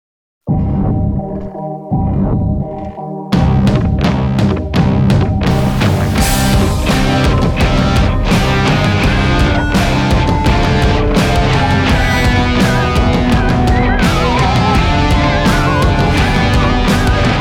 rock музыка